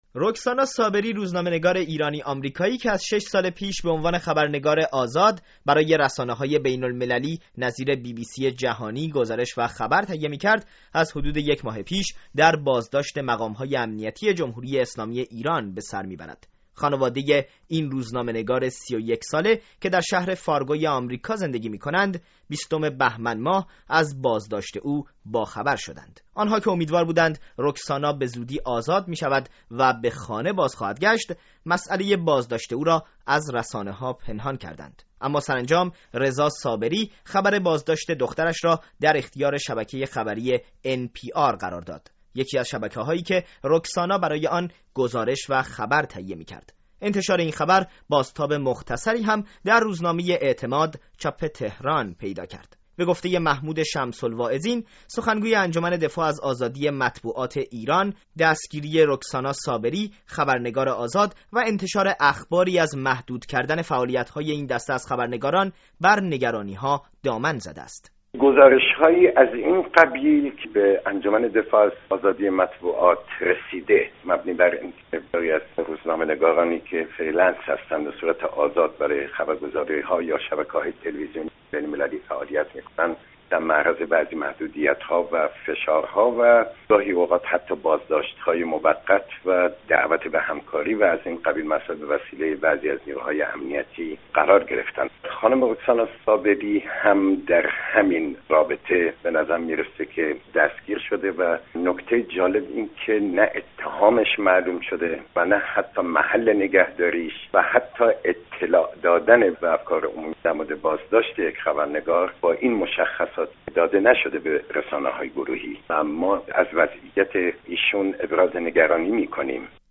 گزارش رادیویی در باره بازداشت خبرنگار ایرانی - آمریکایی